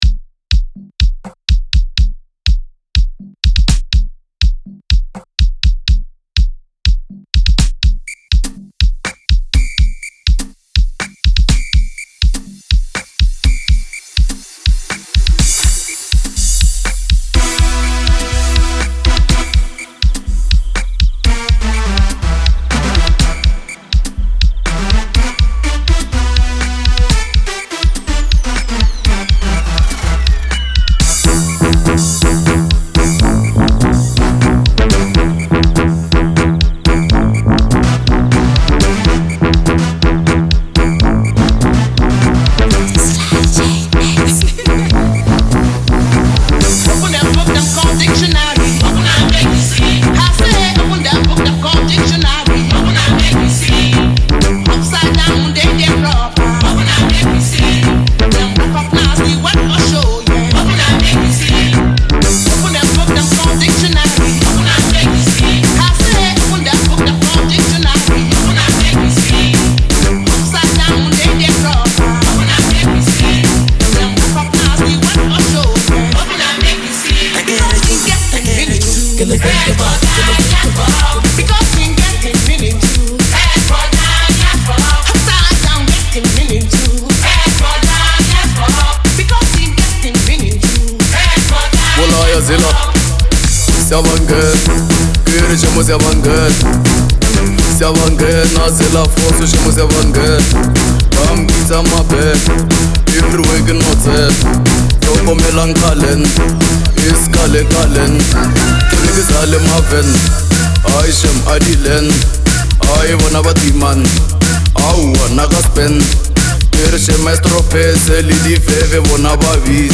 The energetic track